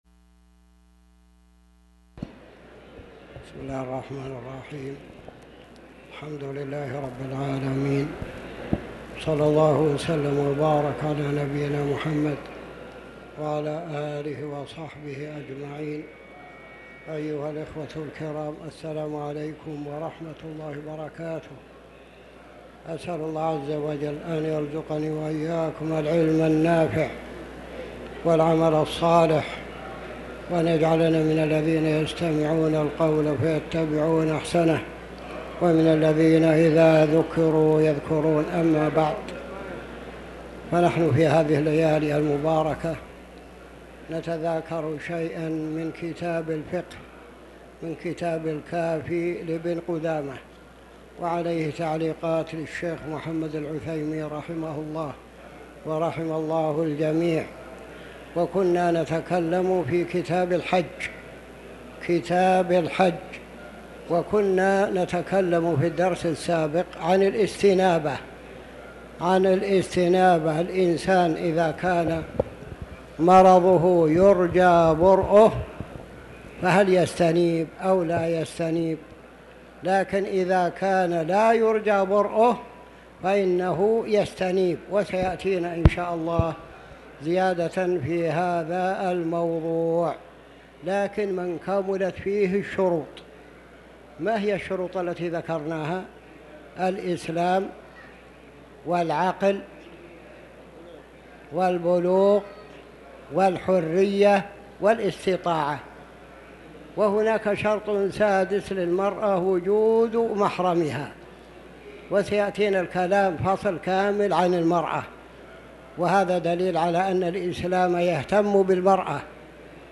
تاريخ النشر ٢١ ذو القعدة ١٤٤٠ هـ المكان: المسجد الحرام الشيخ